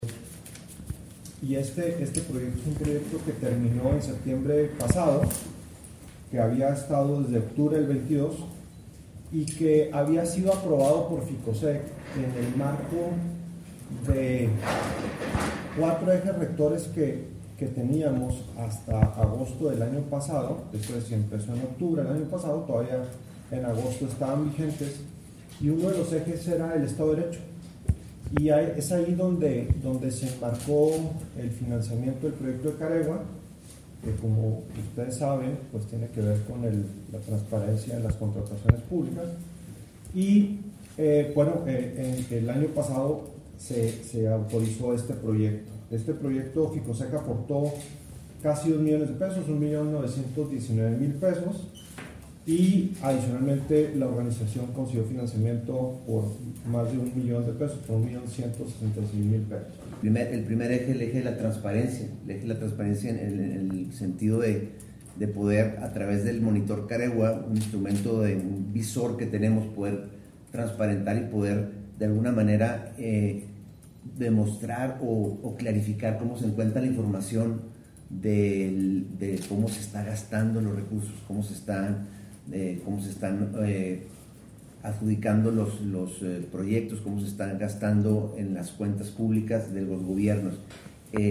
AUDIO: REPRESENTANTE DE KAREWACUU A.C.
REPRESENTANTE-DE-KAREWACUU-A.C.mp3